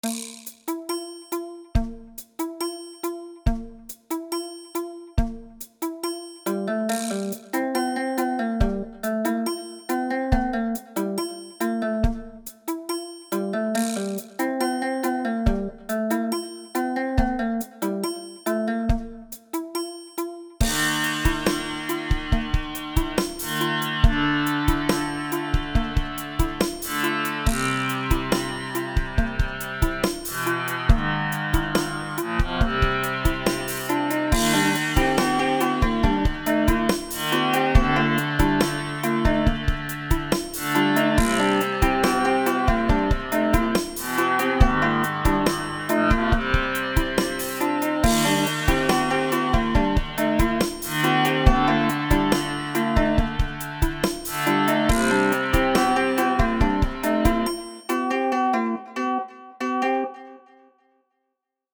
A short proof-of-concept piece in 30edt